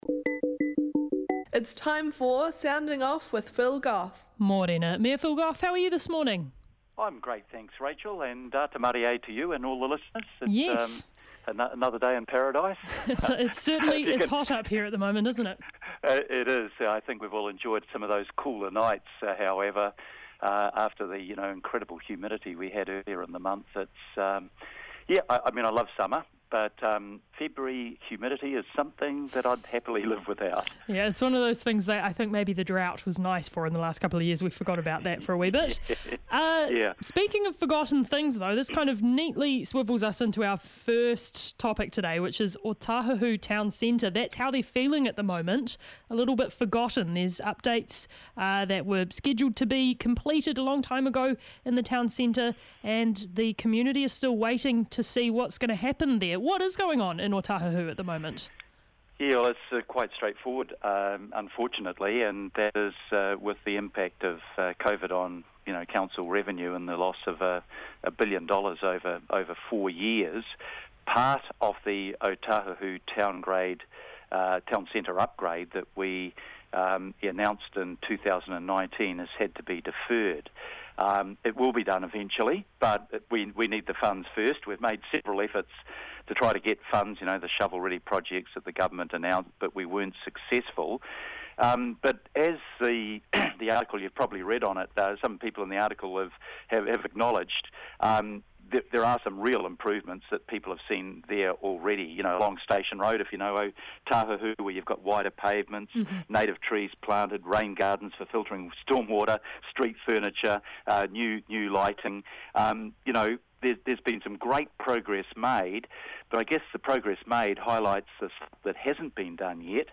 The Mayor chats about frustration at the Ōtāhuhu town centre upgrade, the regional fuel tax, and how we're doing with the omicron outbreak.